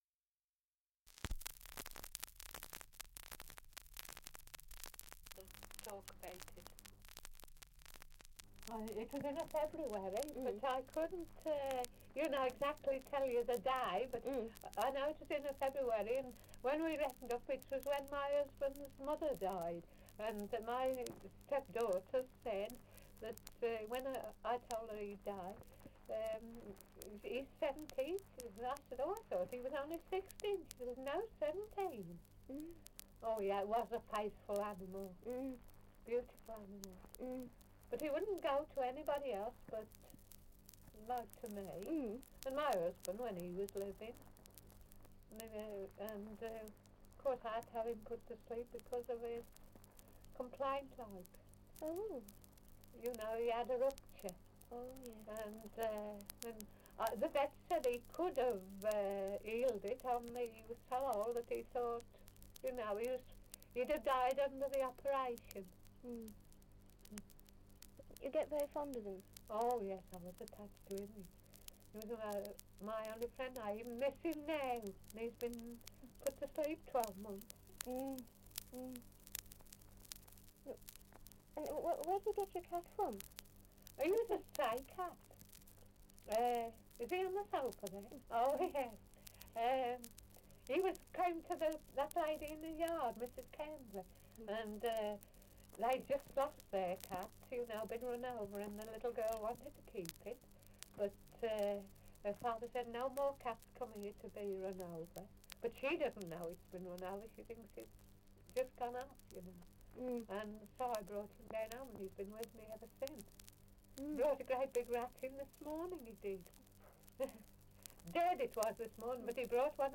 Dialect recording in Albrighton, Shropshire
78 r.p.m., cellulose nitrate on aluminium